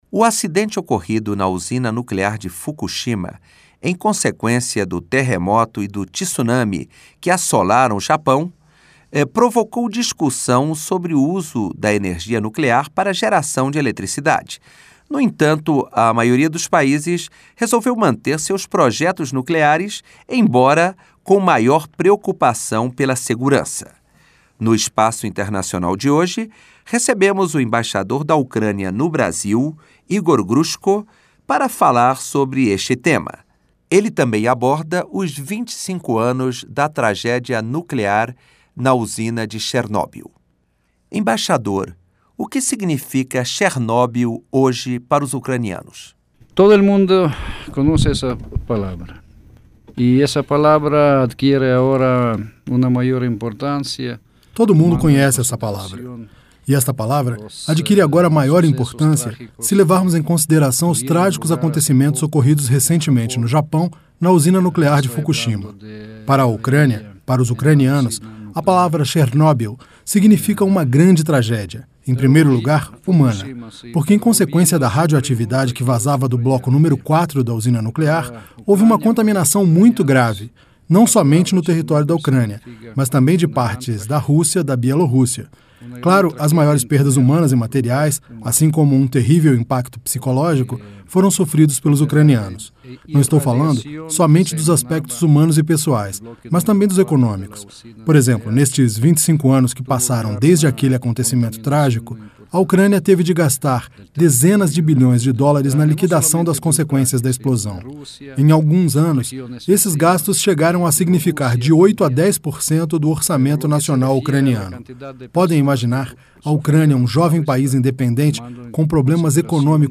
Entrevista com Igor Hruchko, embaixador da Ucrânia no Brasil.